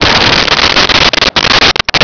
Sfx Crash Gate
sfx_crash_gate.wav